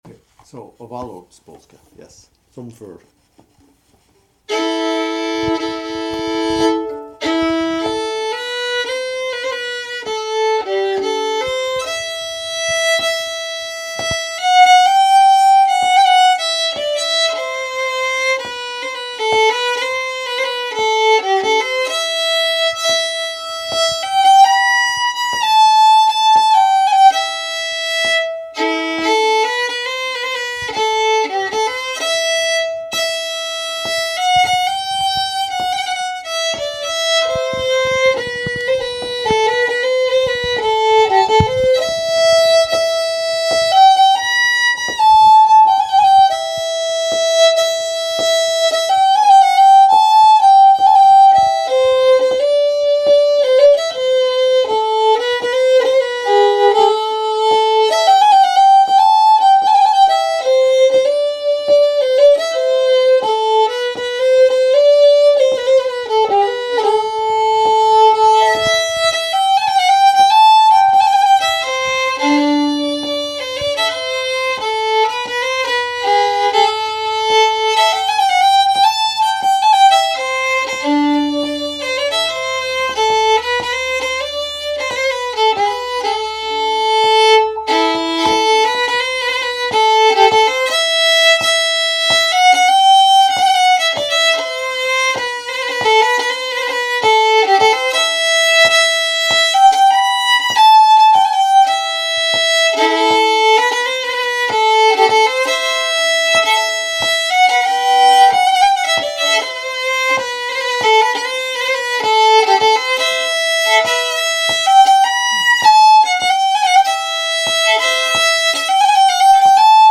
1027 Som Förr, polska